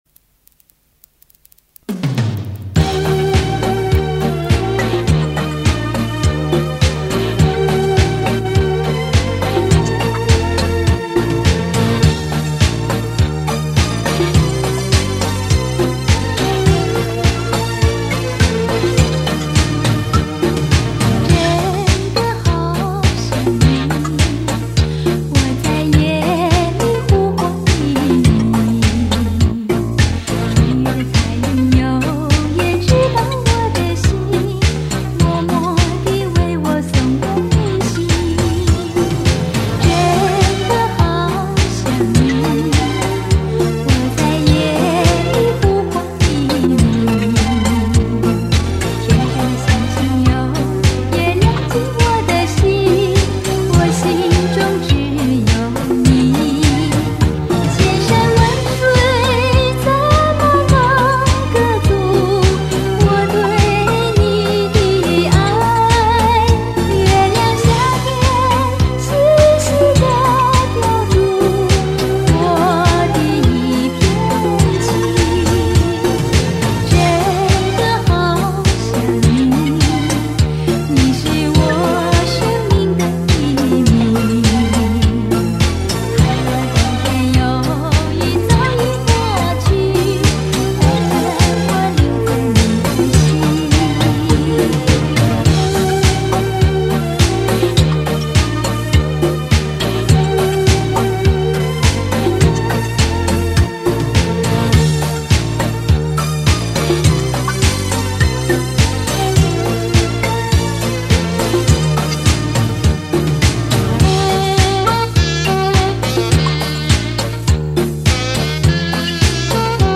格式；磁带----录制无损WAV.(采取了与音像公司设置的段落同步录制。)